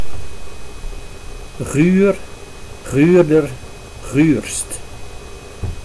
Ääntäminen
US : IPA : [blik]